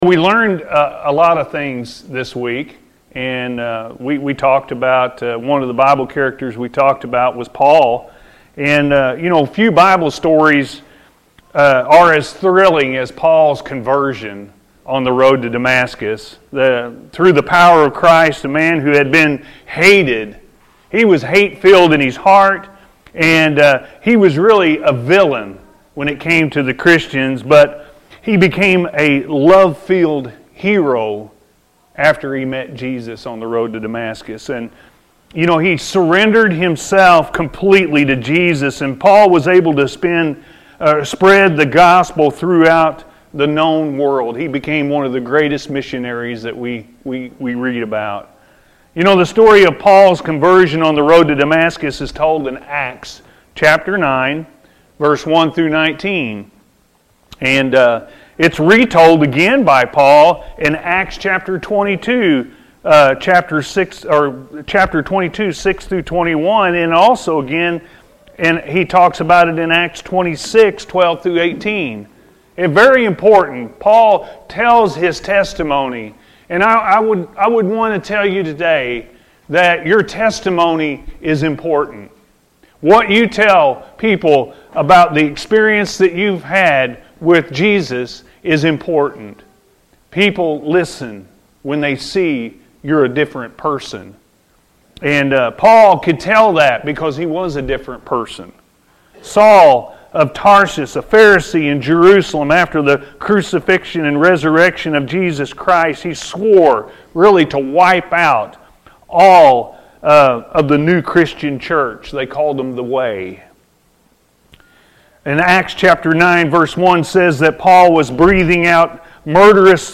Paul’s Testimony-A.M. Service